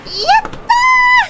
Worms speechbanks
Victory.wav